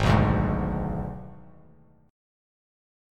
Abdim chord